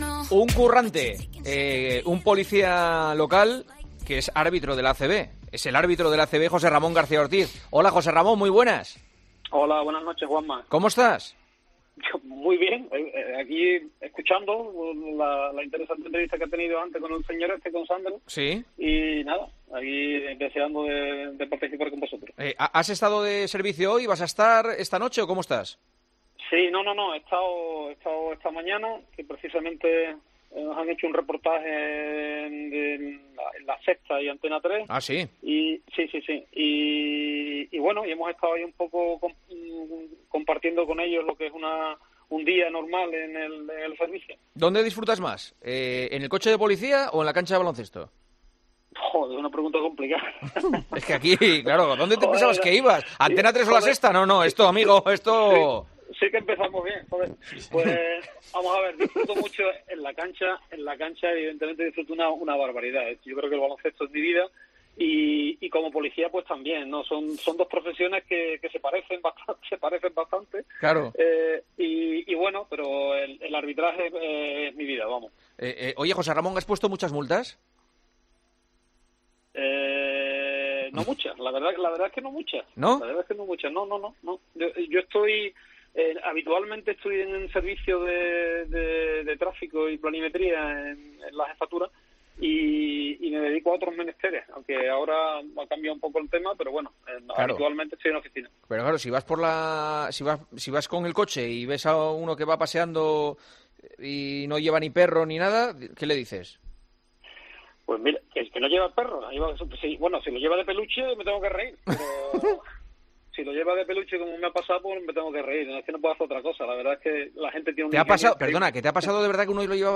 árbitro y Policía Local en los micrófonos de El Partidazo de COPE